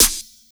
Waka SNARE ROLL PATTERN (47).wav